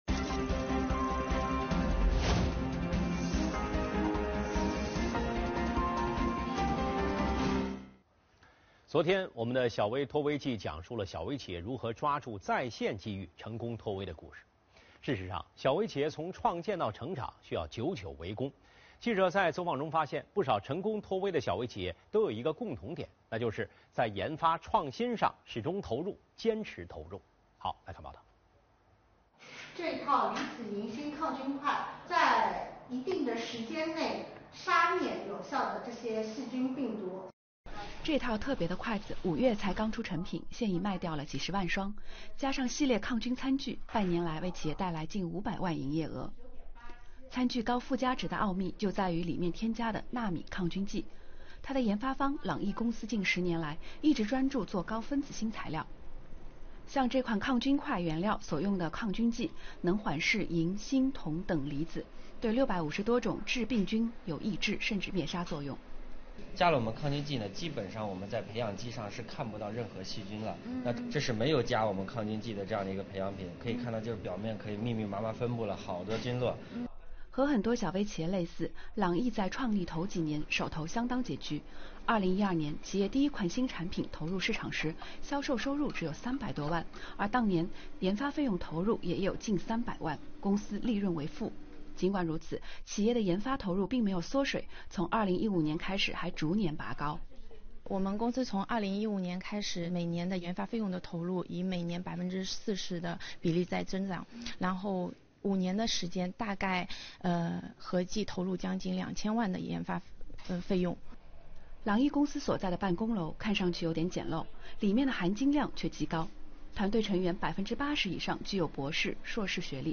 本周，上海电视台《新闻透视》栏目推出3集系列报道，探寻多家小微企业脱“微”背后的秘笈。